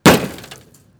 damage.wav